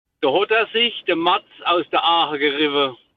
im pfälzischen Dialekt so fort: